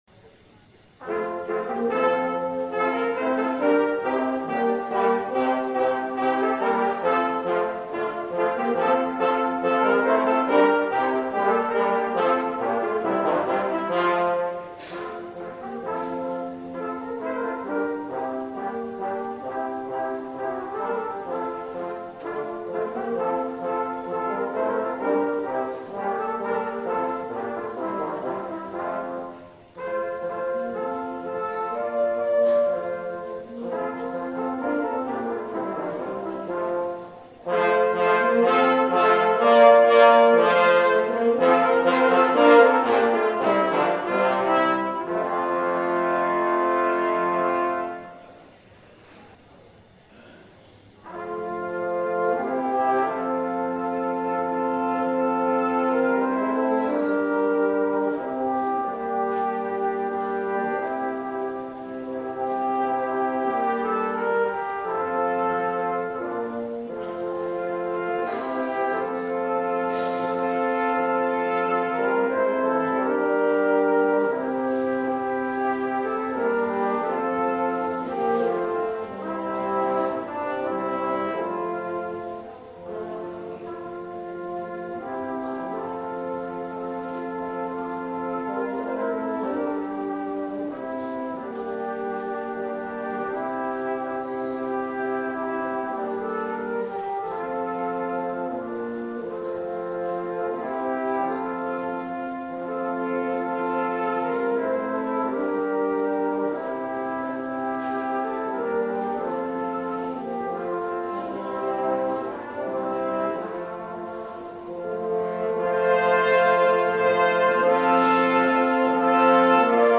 Brass Preludes
Special Brass preludes for Easter Sunday at the Second Reformed Church of Hackensack